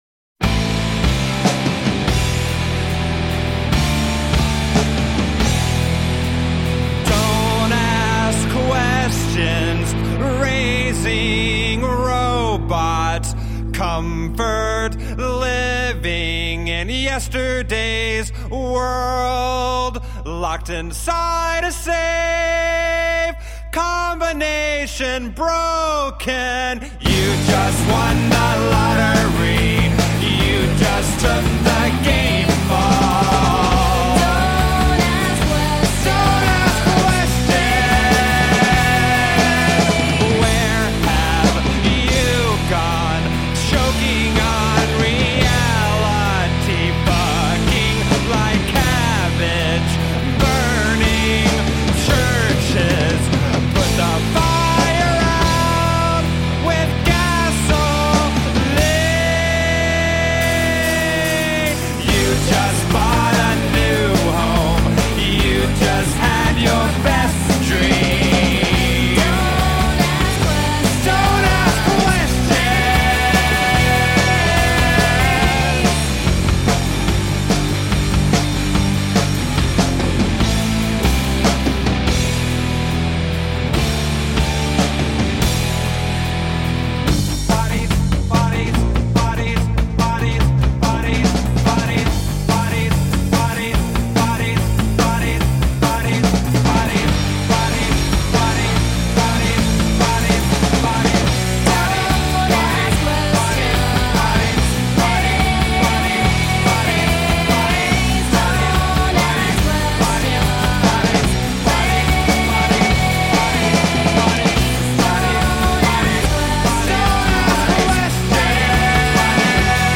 Tagged as: Alt Rock, Rock